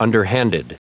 Prononciation du mot underhanded en anglais (fichier audio)
Prononciation du mot : underhanded